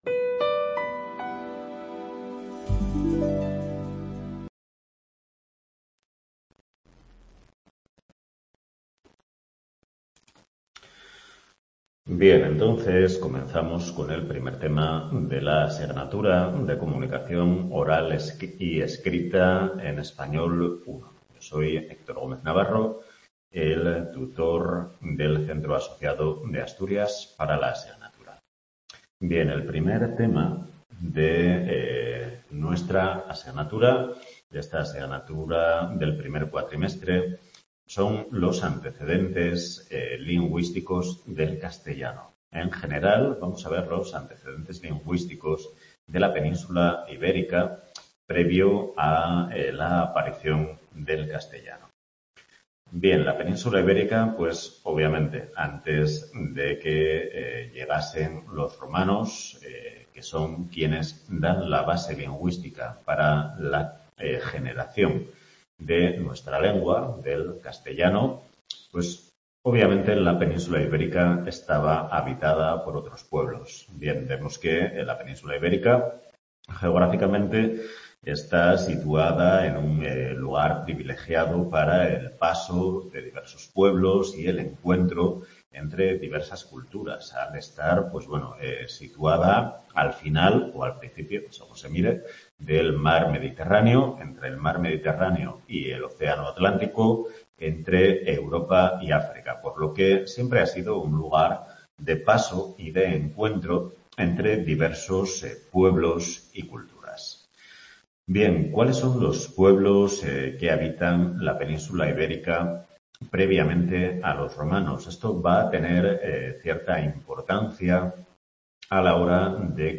Primera clase de Comunicación Oral y Escrita en Español I